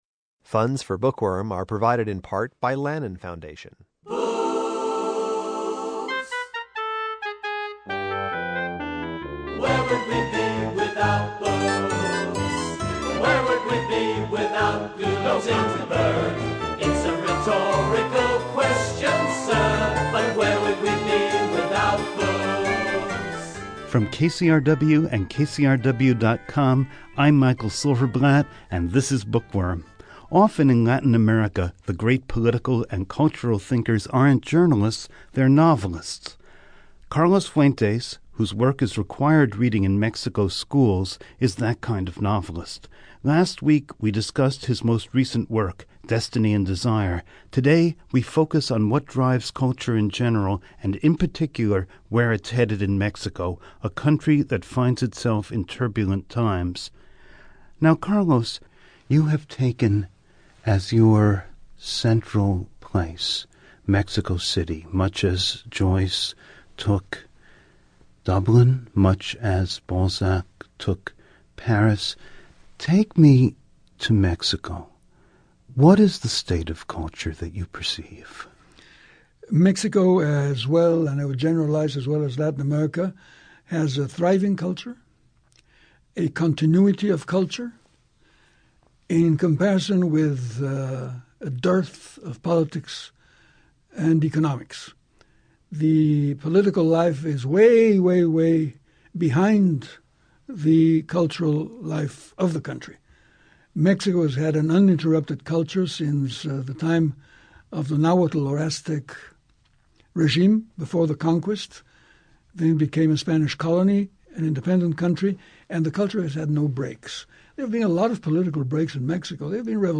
(Part I of this two-part conversation airs on March 17.)